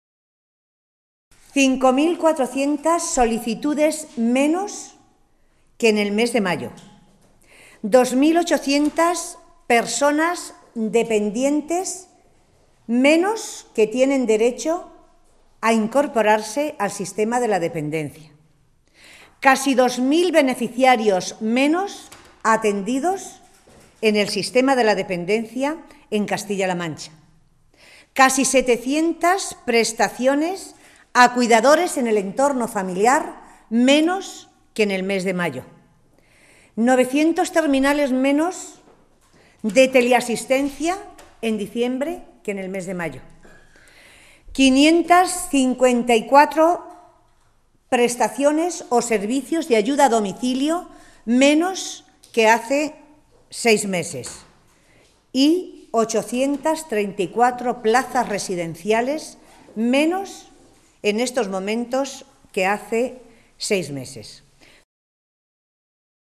Matilde Valentín, portavoz en materia de Asuntos Sociales del Grupo Parlamentario Socialista
Cortes de audio de la rueda de prensa